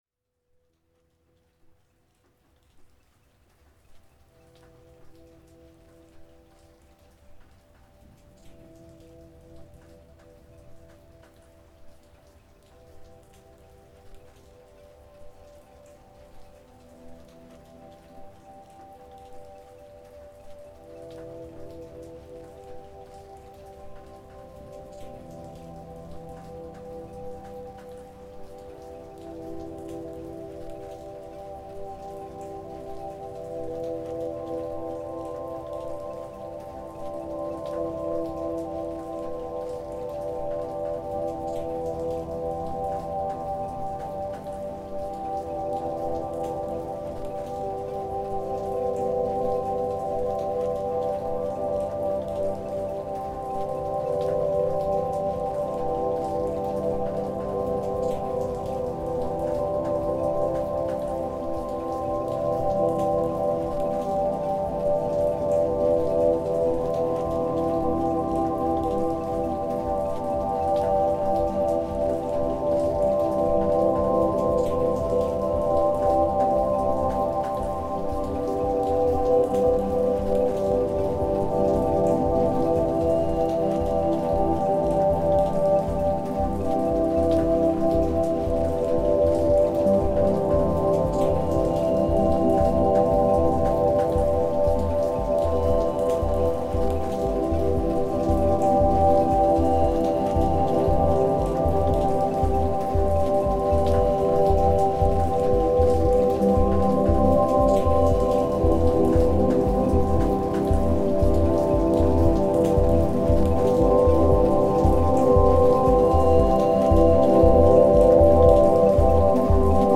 Genre: Ambient/Dub Techno/Deep Techno.